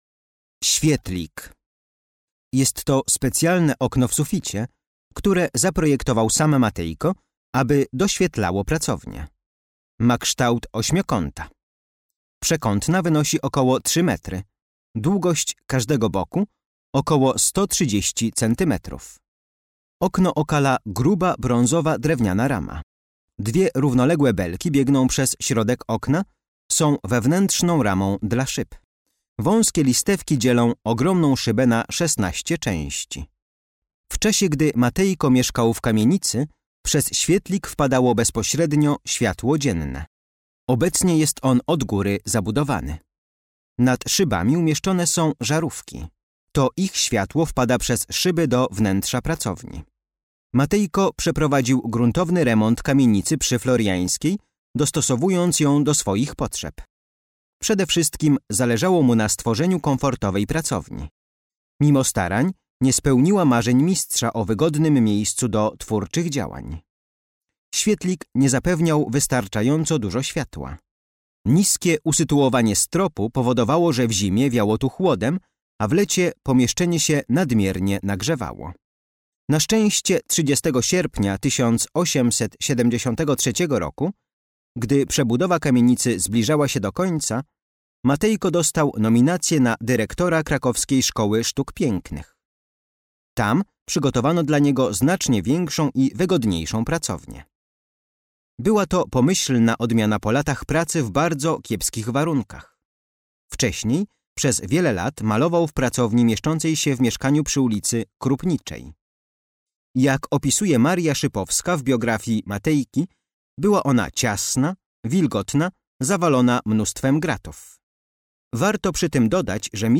Audiodeskrypcja dla wybranych eksponatów z kolekcji MNK znajdujących się w Domu Jana Matejki.